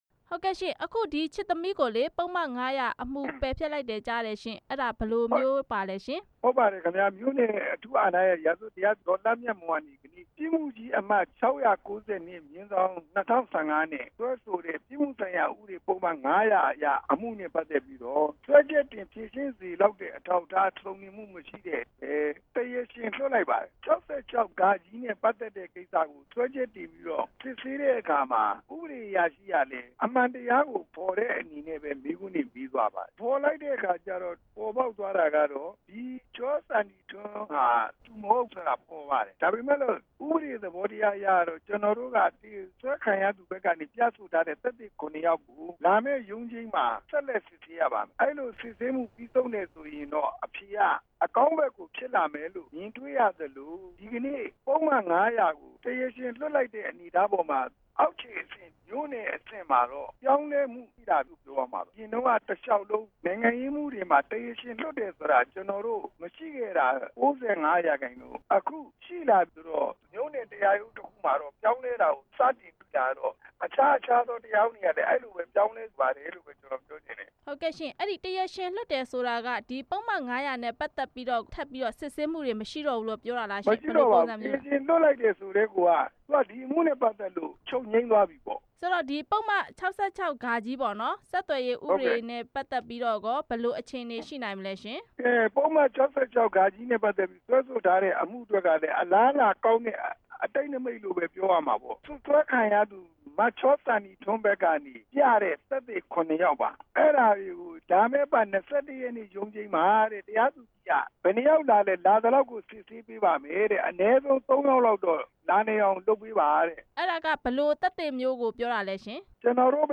ပုဒ်မ ၅၀၀ ပယ်ဖျက်ကြောင်း မေးမြန်းချက်